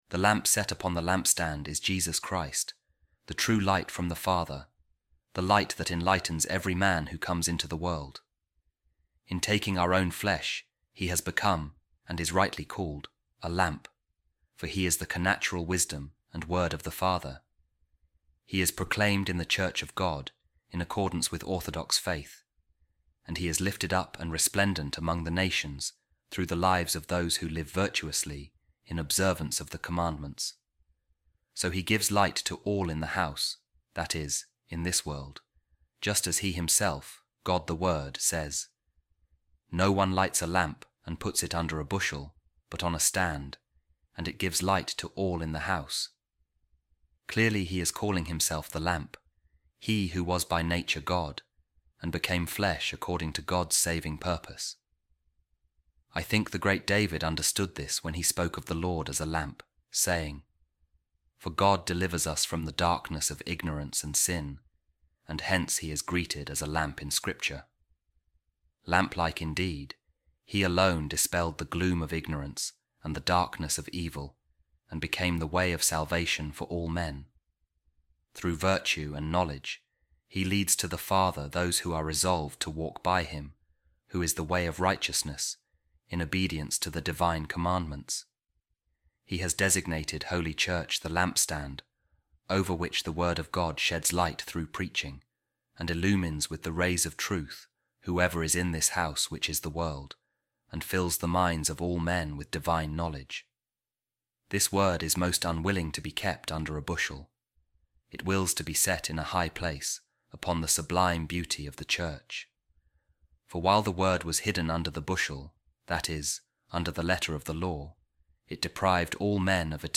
A Reading From The Discourses Of Saint Maximus The Confessor Addressed To Thalassius | The Light That Enlightens Every Man